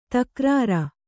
♪ takrāra